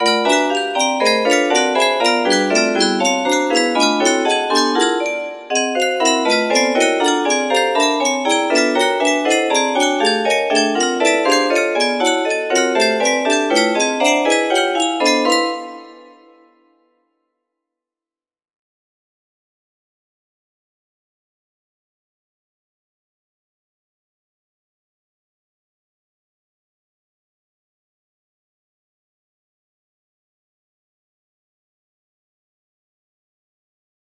P13 music box melody